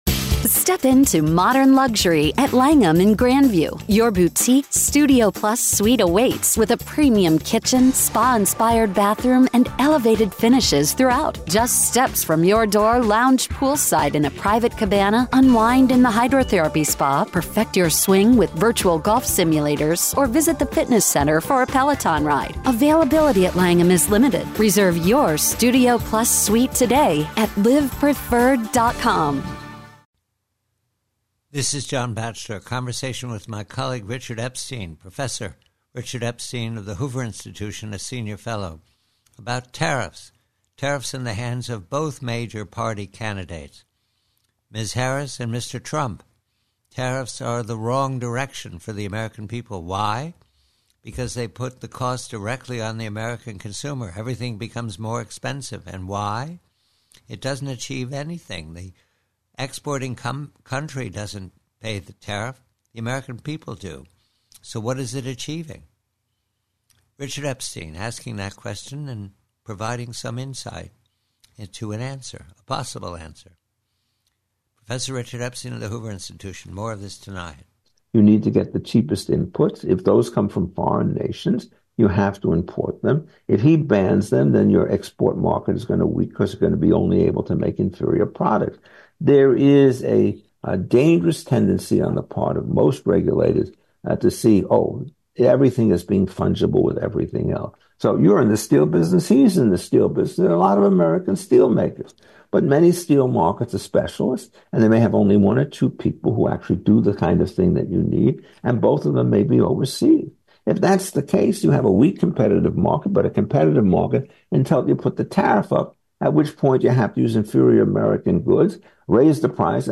PREVIEW: TARIFFS: TRUMP: HARRIS Conversation with Professor Richard Epstein of the Hoover Institution regarding how both major candidates are deceiving themselves, if not the American consumer, on the fact that protectionism raises prices for everyone ex